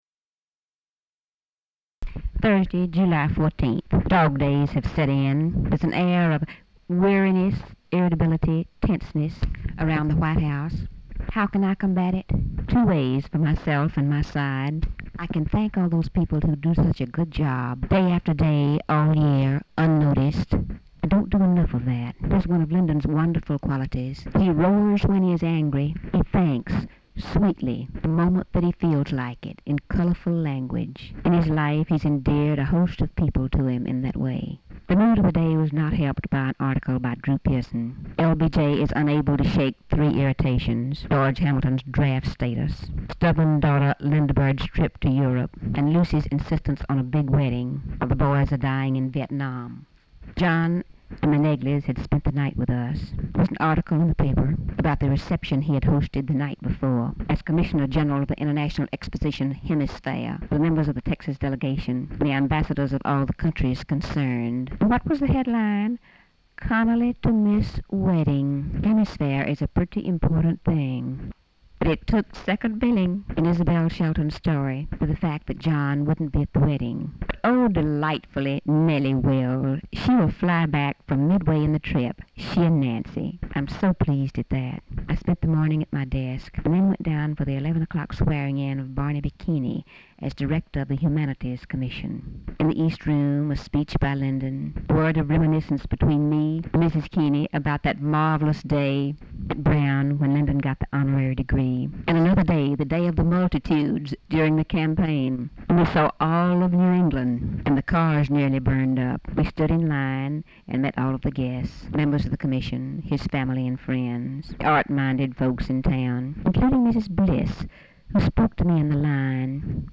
Audio diary and annotated transcript, Lady Bird Johnson, 7/14/1966 (Thursday)